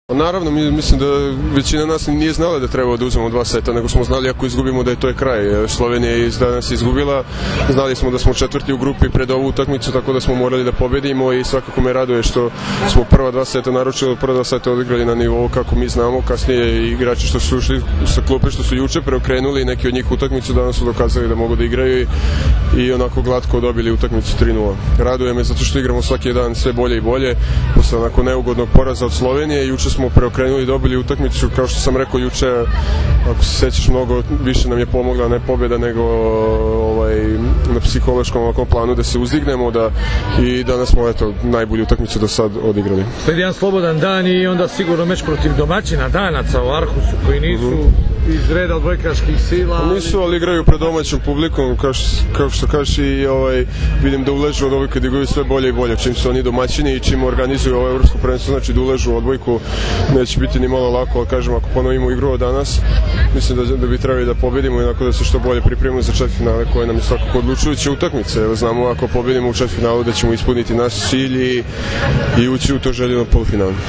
IZJAVA MARKA PODRAŠČANINA